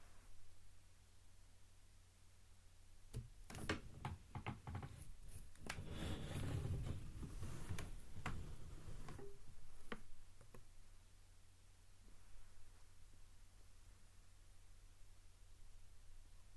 Slow/Open end drawer
Environment - Bedroom, absorption eg, bed, curtains, carpets. Duration - 16 sec Description - Wooden drawer, opens, slowly, drags, till hits the end.